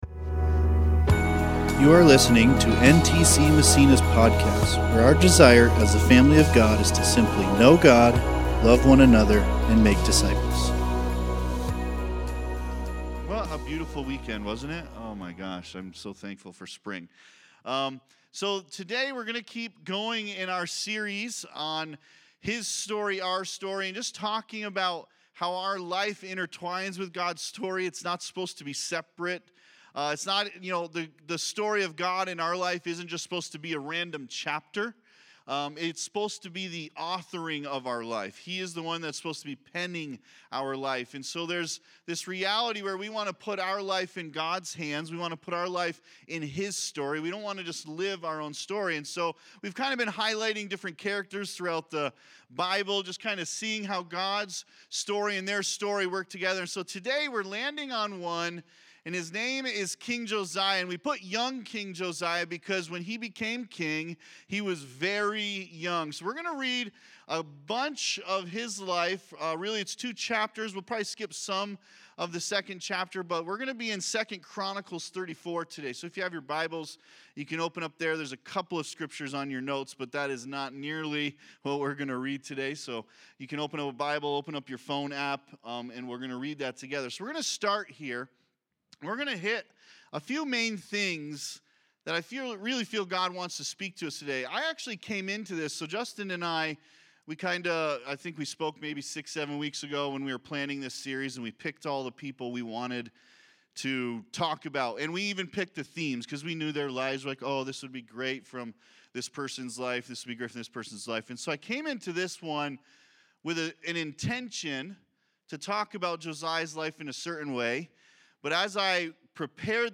This Sunday at NTC Massena, we continue our series on His Story, Our Story.